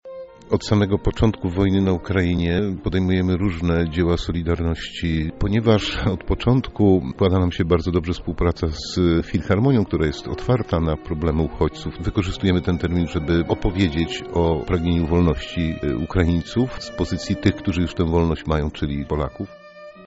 konferencja-filharmonia2.mp3